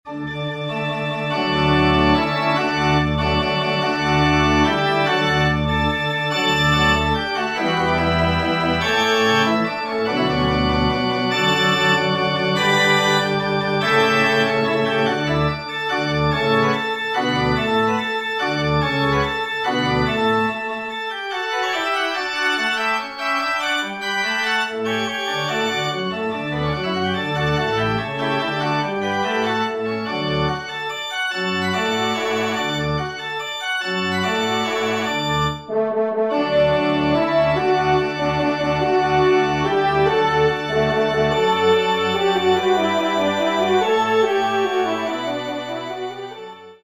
Genre :  Divertissement pour Trompe ou Cor & Orgue
ENSEMBLE (extrait de 45 sec.)